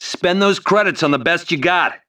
、 分类:维和步兵语音 您不可以覆盖此文件。